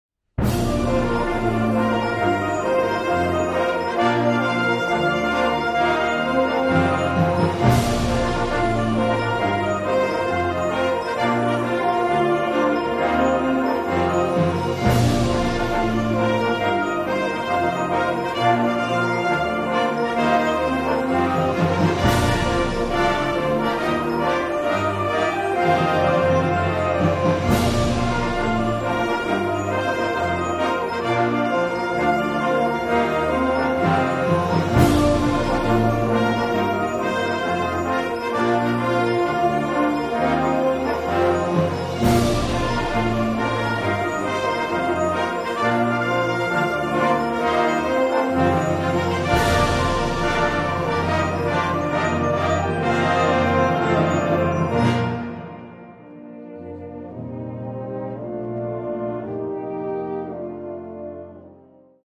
fanfare en slagwerkgroep